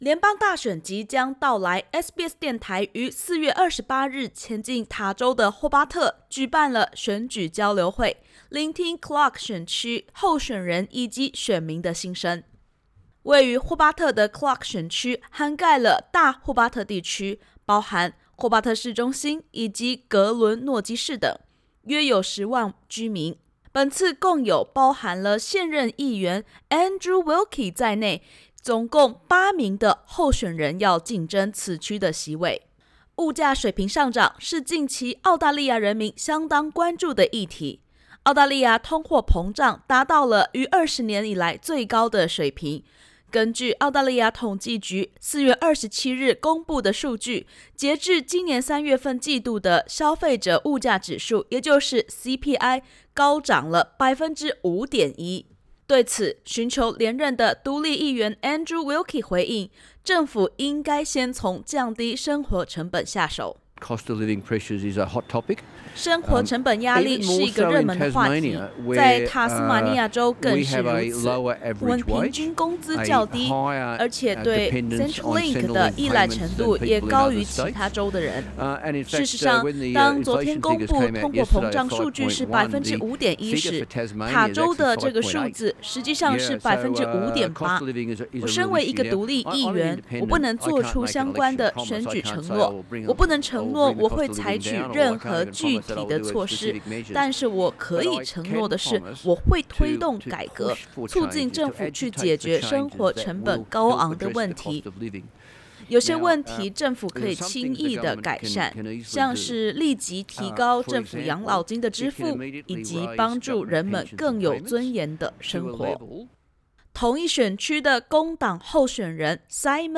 聯邦大選將至，SBS電台於4月28日前往塔州首府霍巴特，舉辦選舉交流會，聆聽Clark選區候選人與選民的心聲。
SBS电台4月28日于霍巴特举办选举交流会。